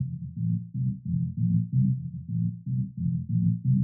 basse boucle1.wav